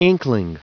Prononciation du mot inkling en anglais (fichier audio)
Prononciation du mot : inkling